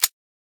grenadepull.ogg